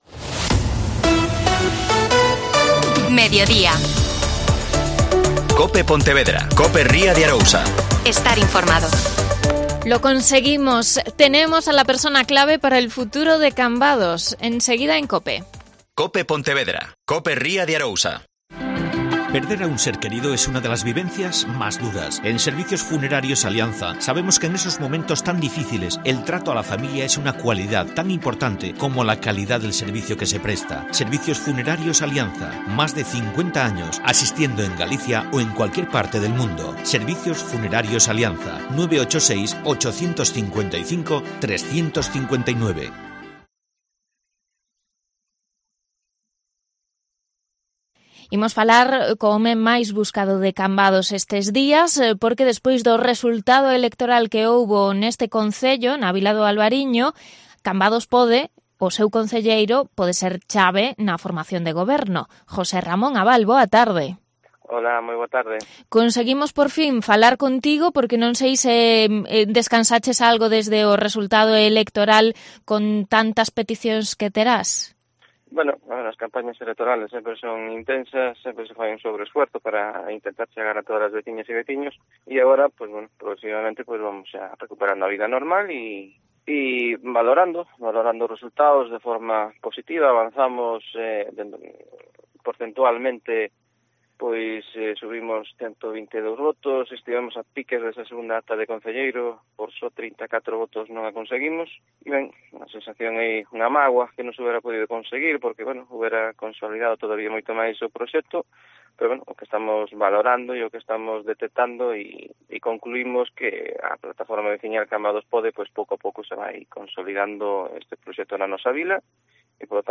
AUDIO: José Ramón Abal. Concejal de "CAMBADOS PODE".